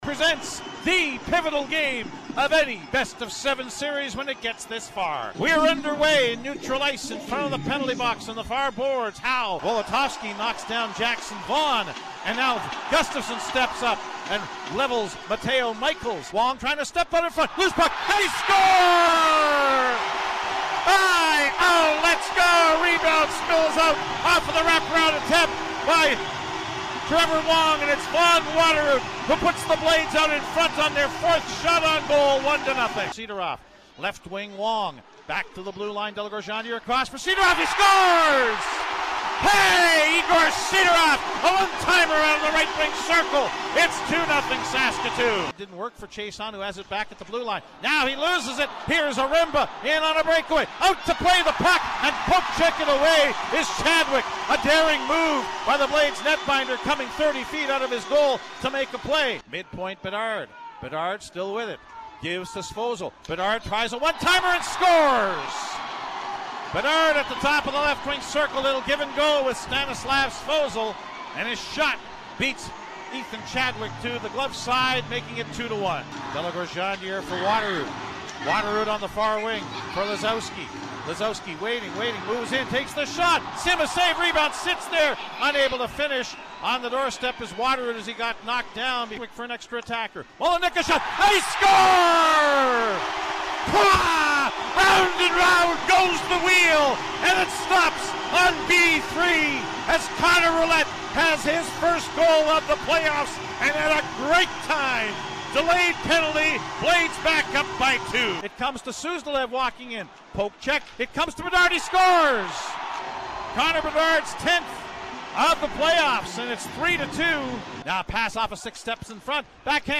the audio highlights from CJWW’s broadcast